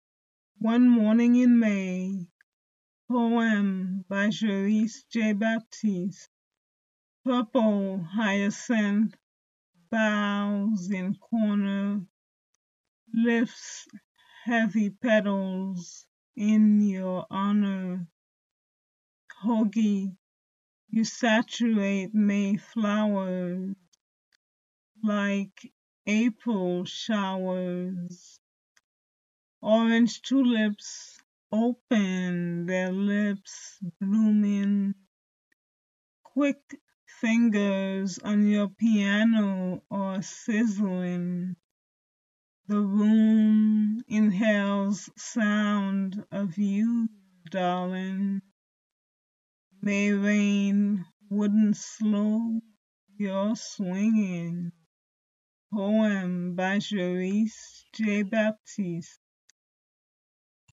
read her poem